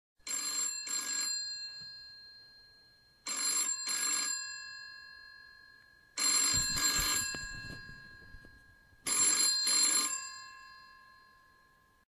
Classic Telefon Klingelton Für Android (Mp3) Und IPhone